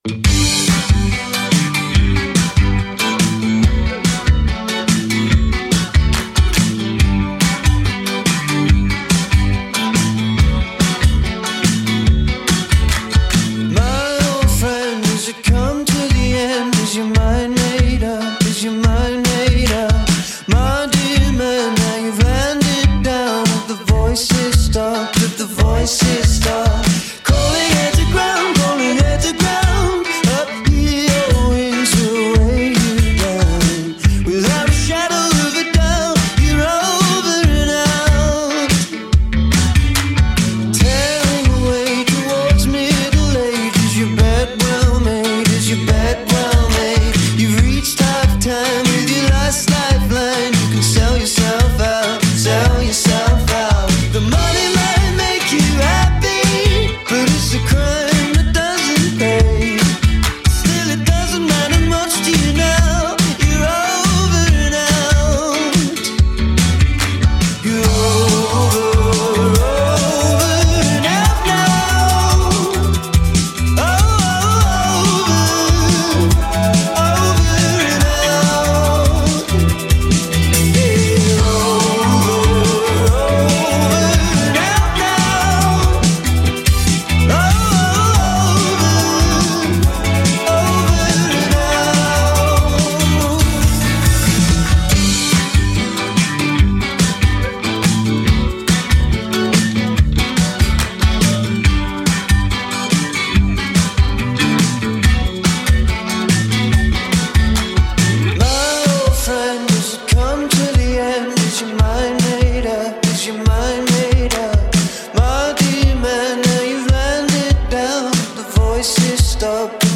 Infectious but hard to categorize
a bit of 80’s New Wave in them–but the good kind